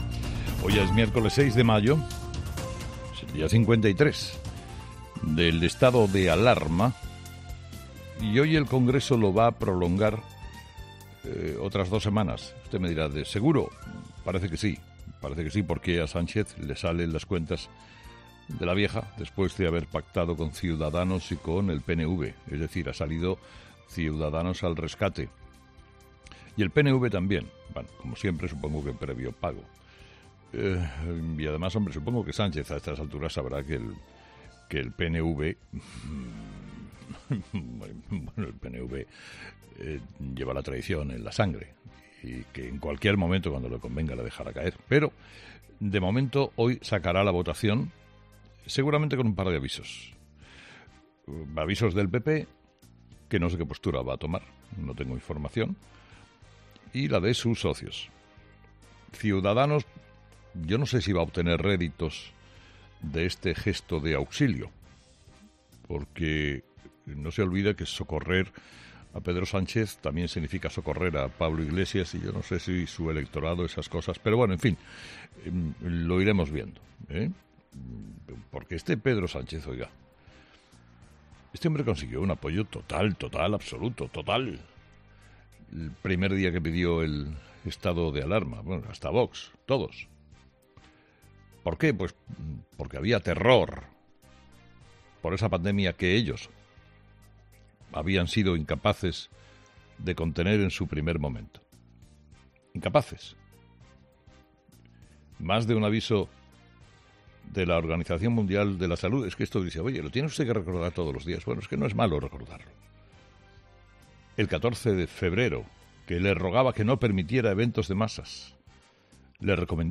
En su monólogo de las seis de la mañana, Carlos Herrera ha comentado la presivisible aprobación de la prórroga del estado de alarma que tendrá lugar este miércoles en el Congreso de los Diputados.